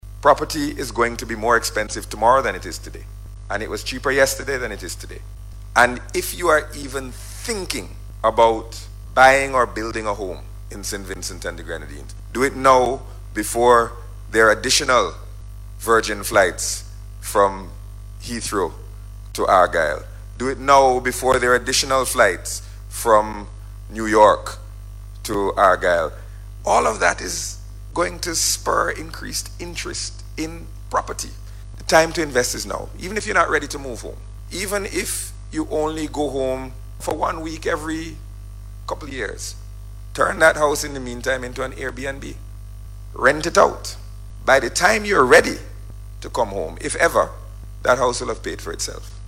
The encouragement came from Minister of Finance, Camillo Gonsalves, as he addressed a range of issues during the Diaspora Outreach and Investment Program, spearheaded by Invest SVG.